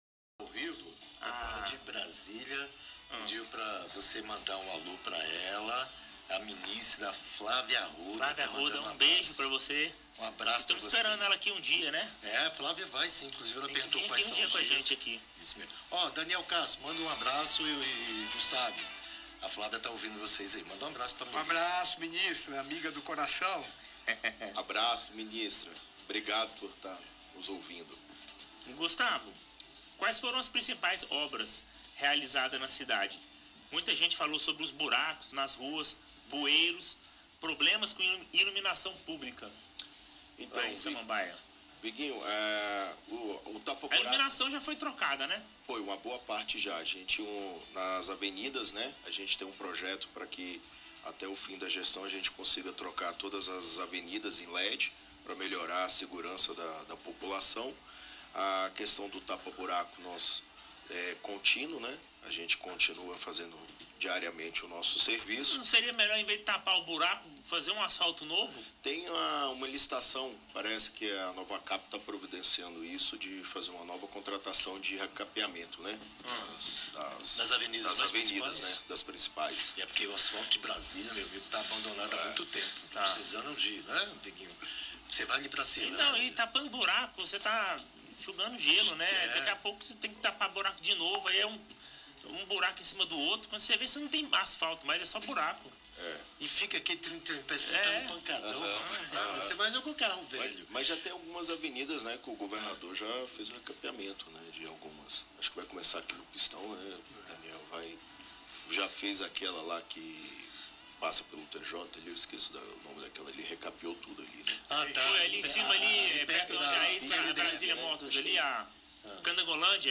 Os administradores regionais de Samambaia, Gustavo Aires, e de Vicente Pires, Daniel de Castro, concederam entrevista ao vivo ao quadro Brasília em Atividade, do programa Clássicos da Atividade, nesta quinta-feira (22).